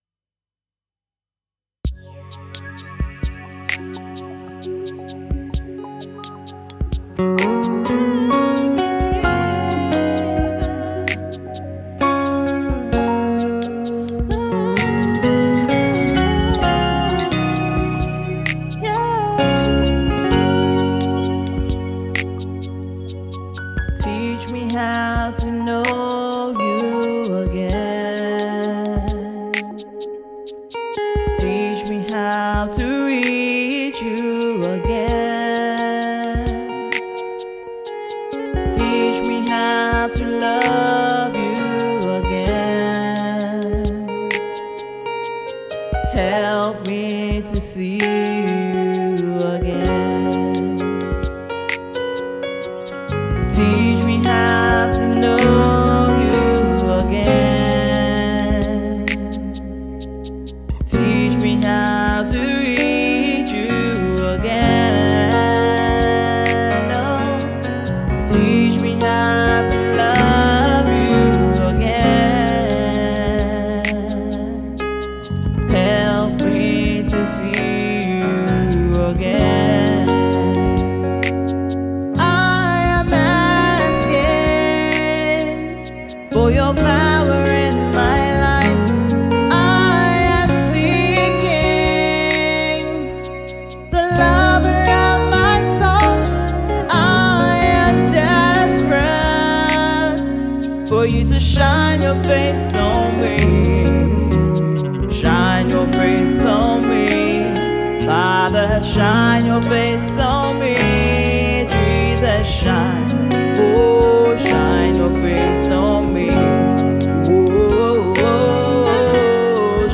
Afro-pop
song of supplication